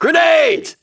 C&C3 Grenadier voice lines (tweaked)
Subject description: de-echoed and converted to ra2 quality   Reply with quote  Mark this post and the followings unread
I reduced echo (not perfect) and additionally tweaked one or 2 on top of that.
*Includes 22.05kHz converts to make fit with vanilla audio better, and also the original non-downsampled versions